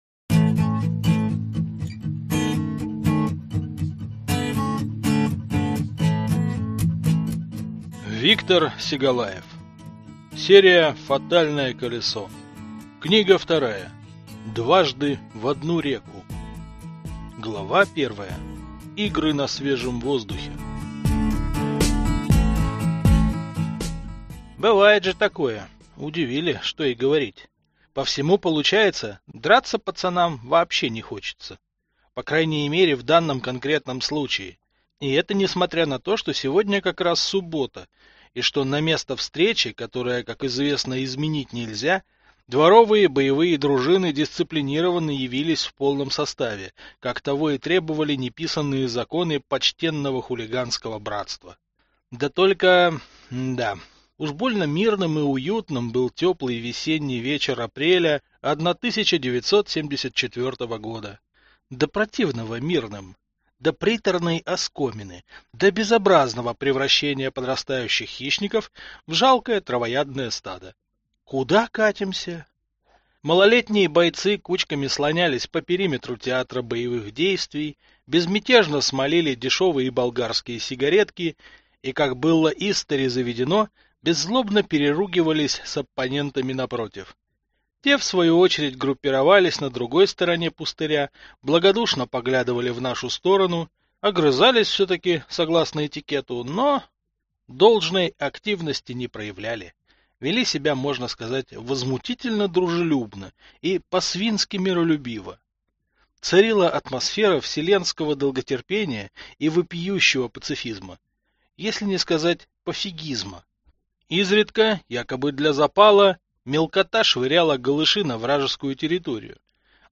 Аудиокнига Фатальное колесо. Дважды в одну реку | Библиотека аудиокниг